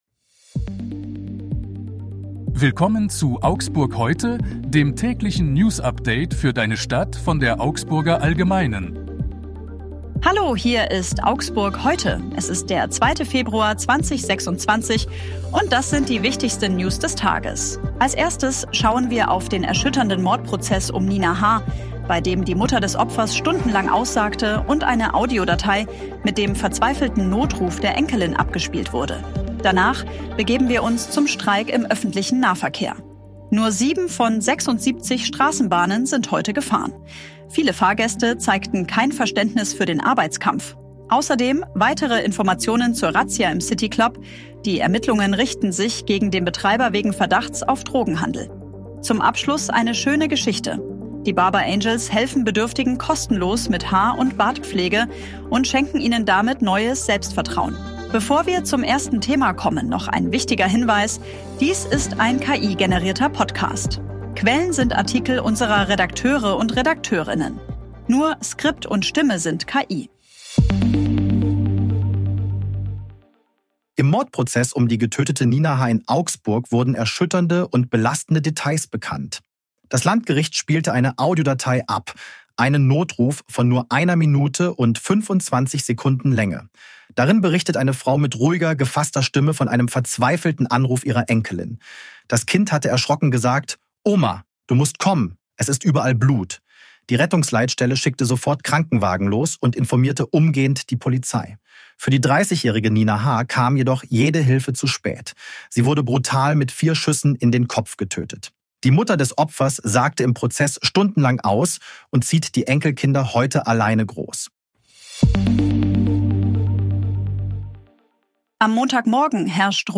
Hier ist das tägliche Newsupdate für deine Stadt.
Stimme sind KI.